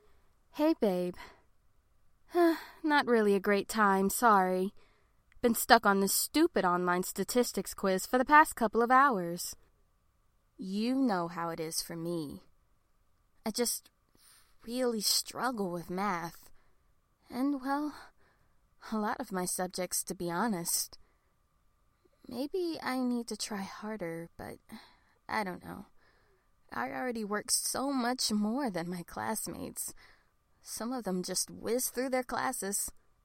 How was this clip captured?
An Introduction – No Enhancements Intro-Sample-No-Enhance.mp3